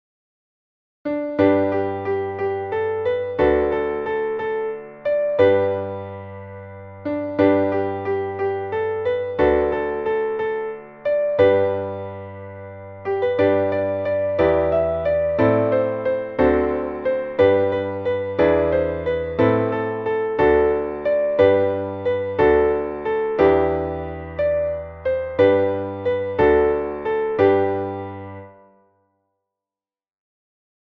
Traditionelles Kinderlied / Volkslied